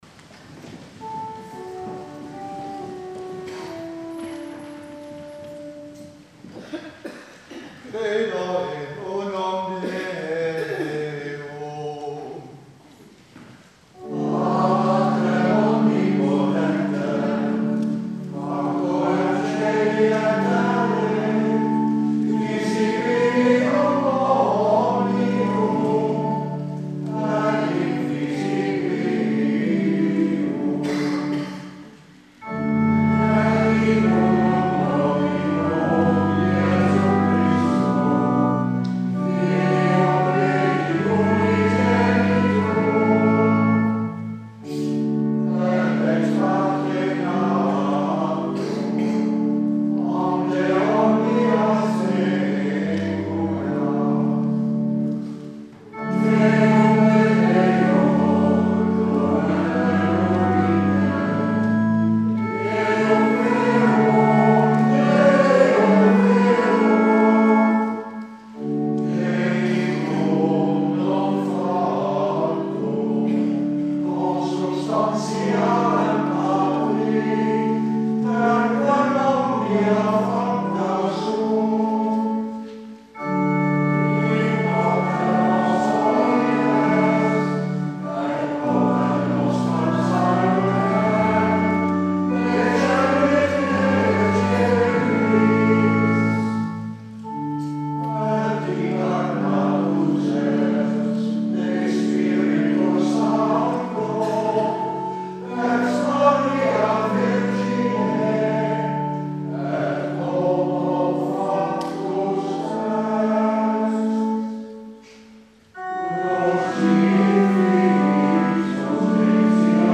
Afscheid Schola 21 juni 2015